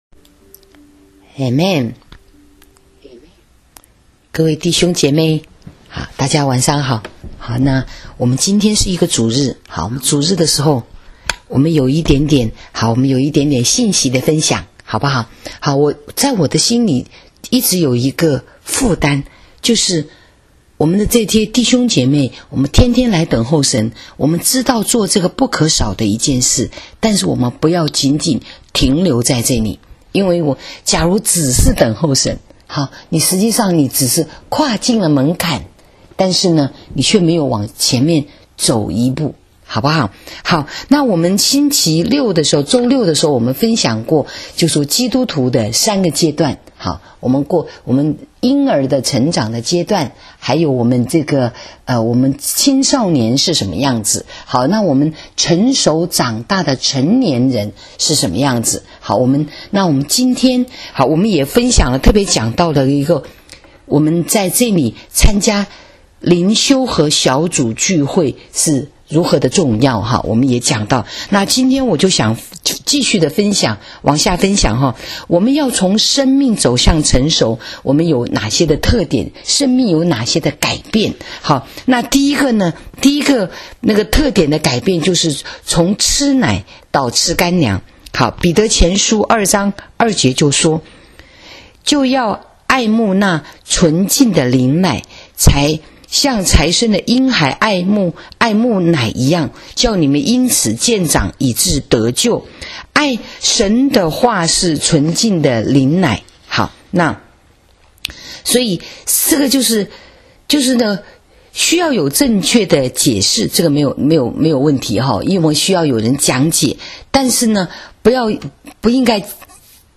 【主日信息】属灵生命的成长（2） （7-6-19）